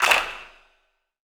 Claps
TC3Clap5.wav